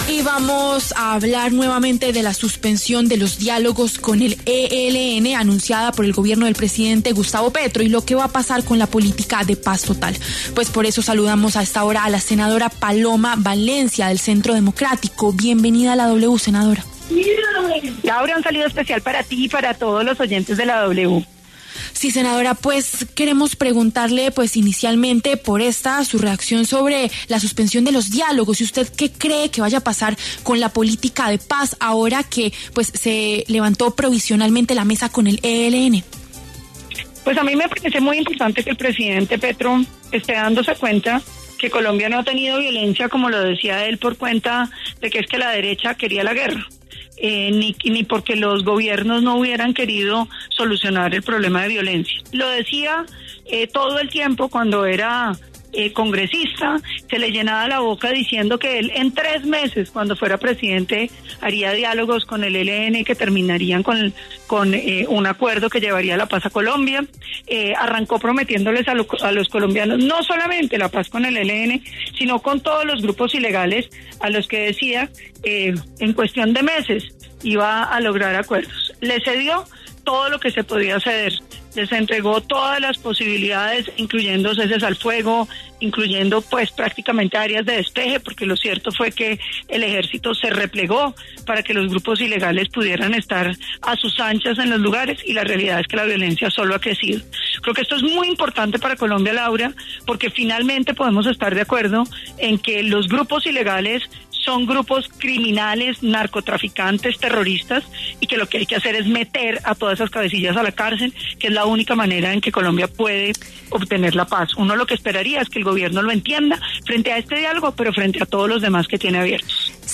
Paloma Valencia, senadora del Centro Democrático, analizó en W Fin De Semana la crítica situación en el Catatumbo por los enfrentamientos entre el ELN y las disidencias de las Farc, dejando a la población civil en medio de la confrontación.
Reviva la entrevista completa con la senadora Paloma Valencia a continuación